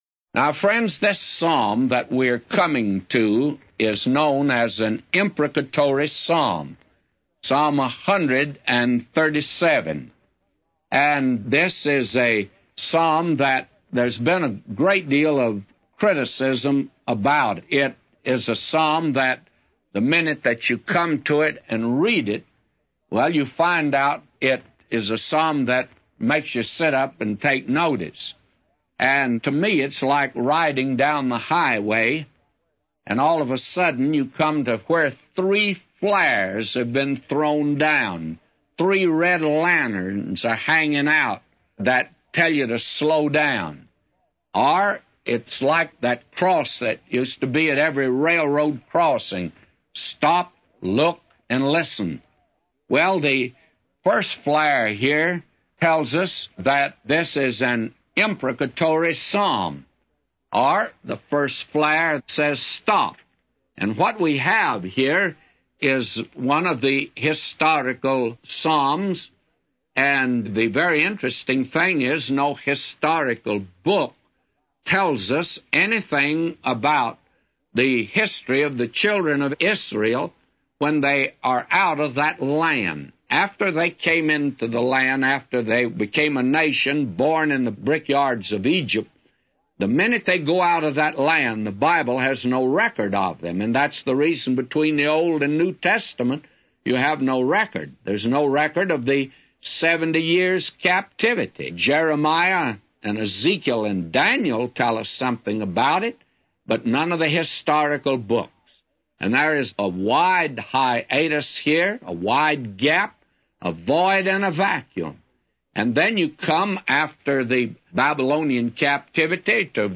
A Commentary